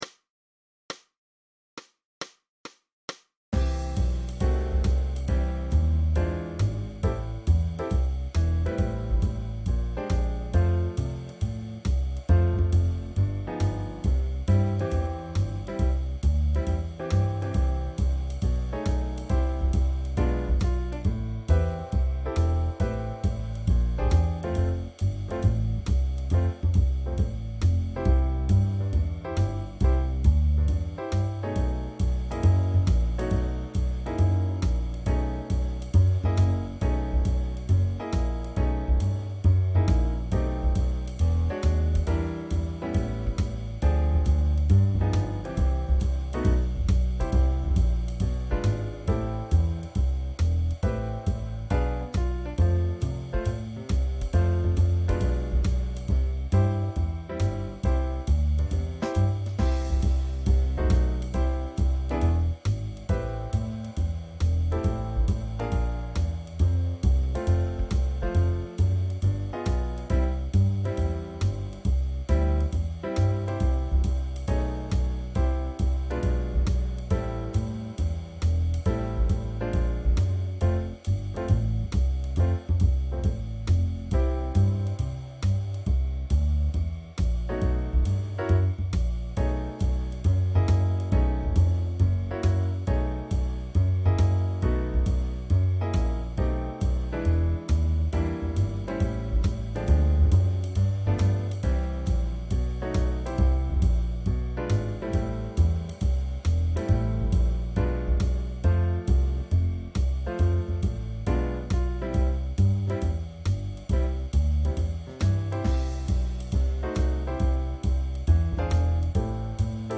High School Jazz Band Practice Tracks 2007-2008
Each tune has a two bar intro (drums)